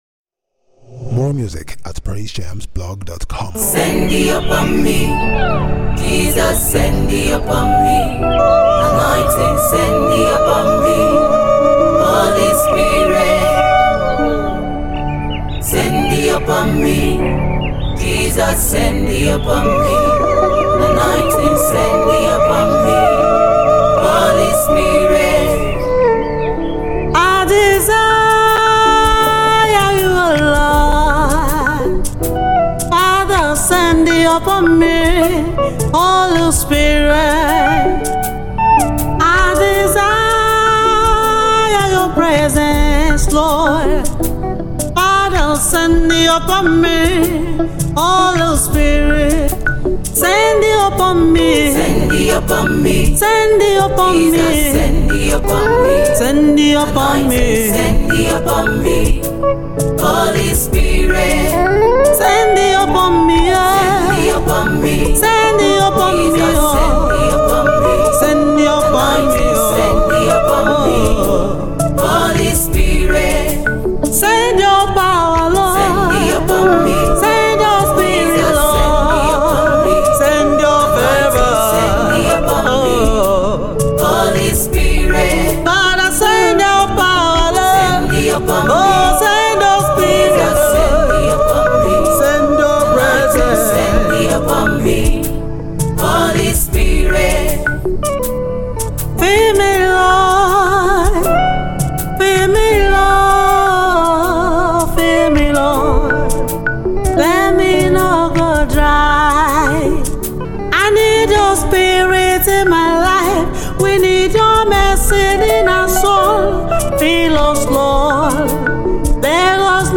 2020-06-04 New Music, Nigeria Gospel Songs 1 Comment